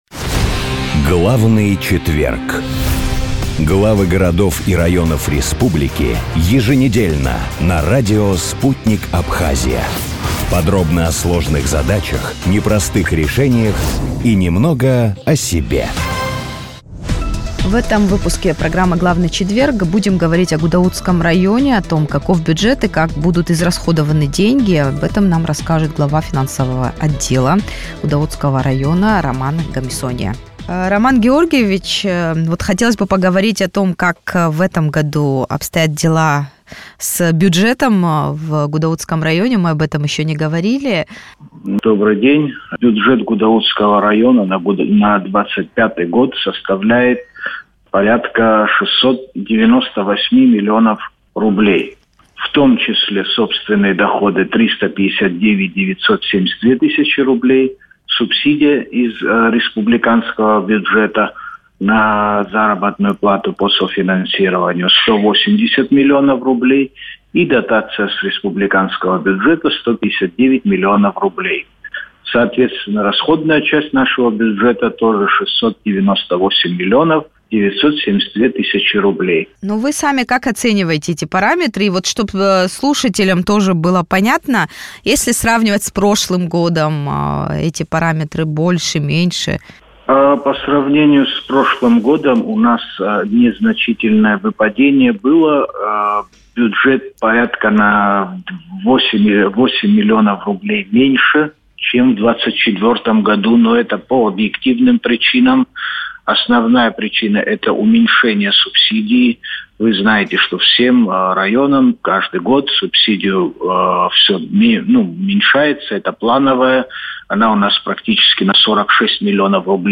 Глава финансового отдела администрации Гудаутского района Роман Гамисония в эфире радио Sputnik Абхазия рассказал, как исполняется бюджет, какие новые объекты могут увеличить поступление в казну и какова годовая динамика финансового роста...